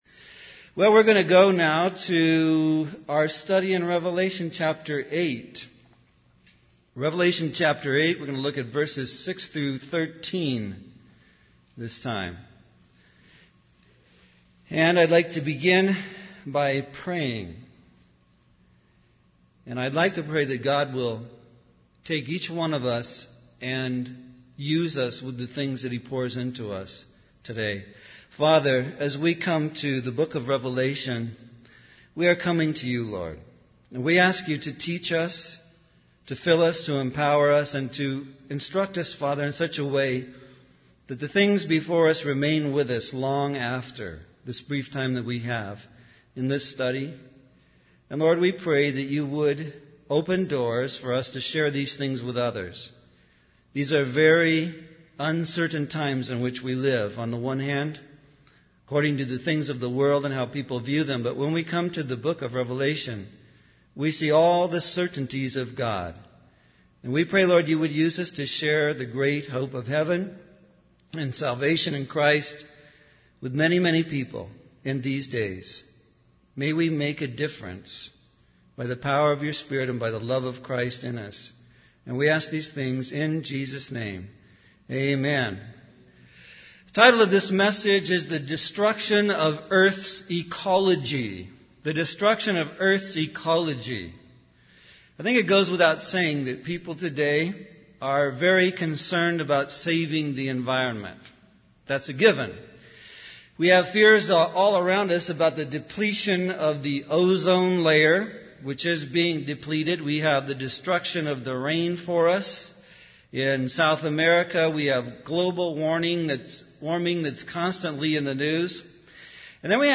In this sermon, the preacher emphasizes the importance of sharing the truth of God's word in a world filled with lies. He highlights that God's judgment begins with the earth after mankind has rejected His teachings. The preacher then focuses on the first trumpet judgment described in Revelation 8:7, where hail and fire are thrown to the earth, resulting in the burning of a third of the trees and all the green grass.